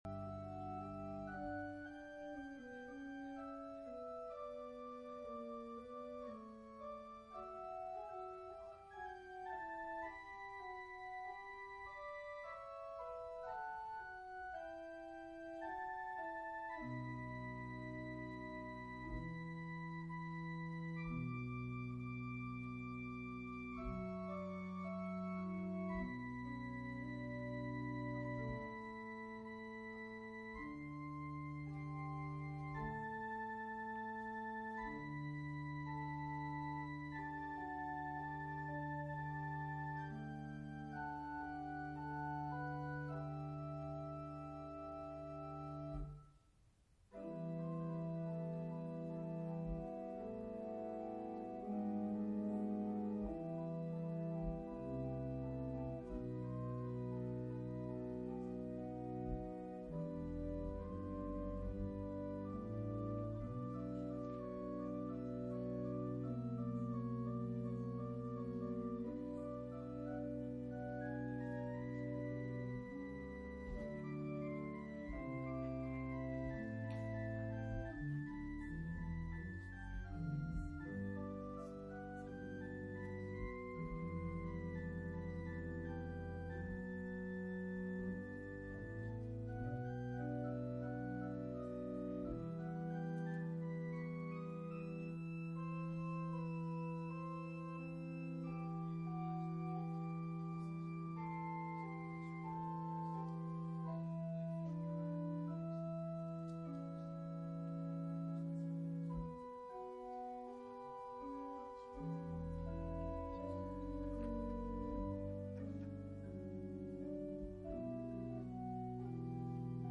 “Ash Wednesday Service”